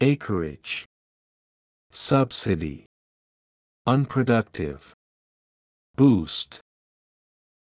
acreage /ˈeɪkərɪdʒ/（名）面積、エーカー数（特に農地の広さを表す）
subsidy /ˈsʌbsɪˌdi/（名）補助金、助成金
unproductive /ˌʌnprəˈdʌktɪv/（形）非生産的な
boost /buːst/（動）押し上げる